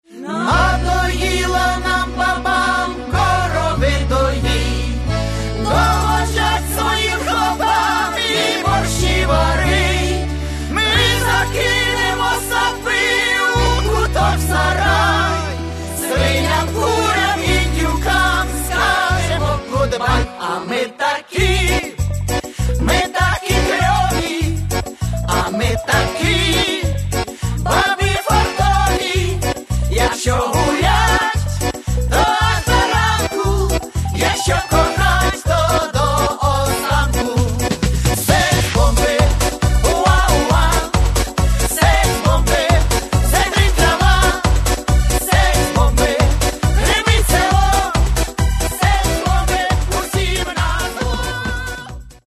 якщо вам хочеться чогось смішного й запального